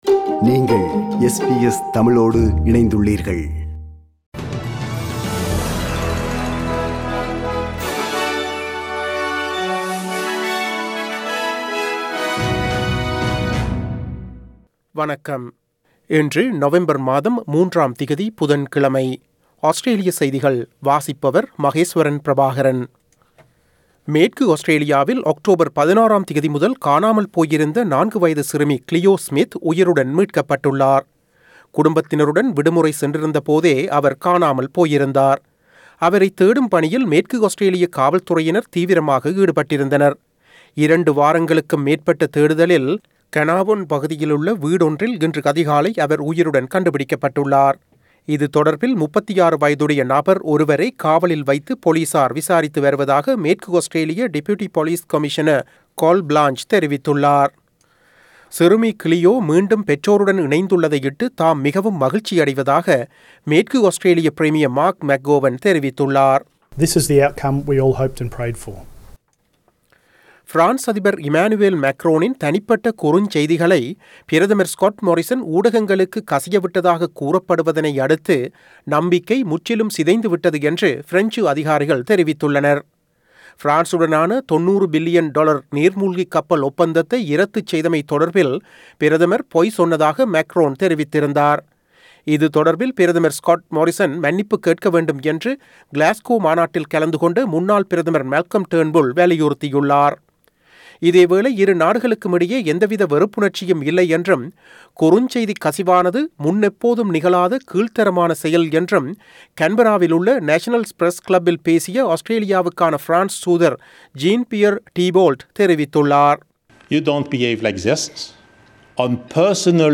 Australian news bulletin for Wednesday 03 November 2021.